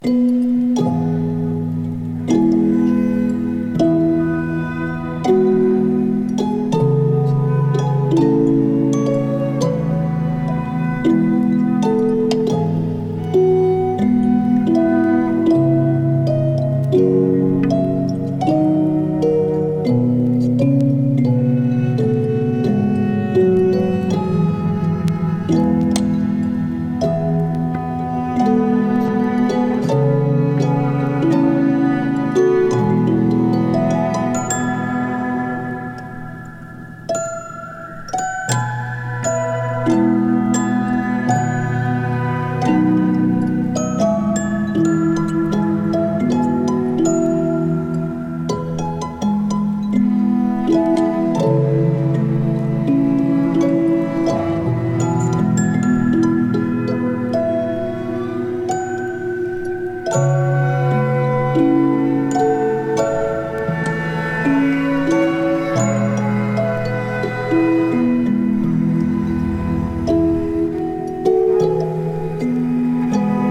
ピアノ、弦楽器、ホルン、ヴァイブ、オルゴールで構成されたインストゥルメンタルの旅。